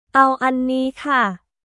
เอาอันนี้ค่ะ　アオ・アンニー・カ